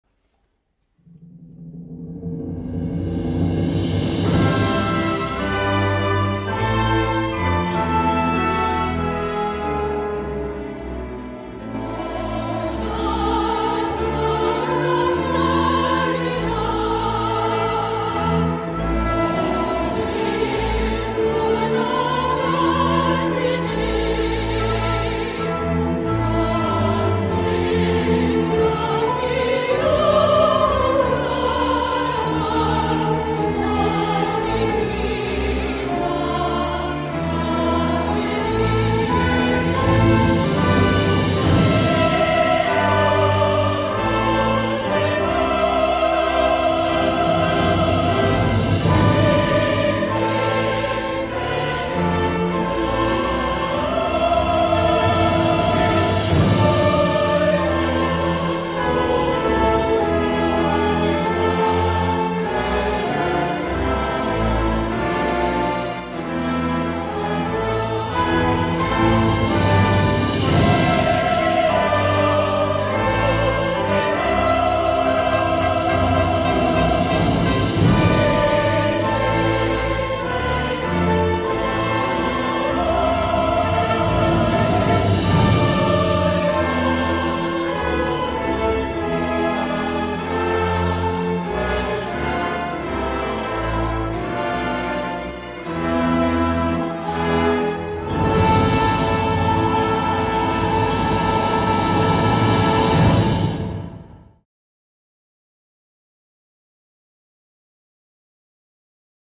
торжественную мелодию
со словами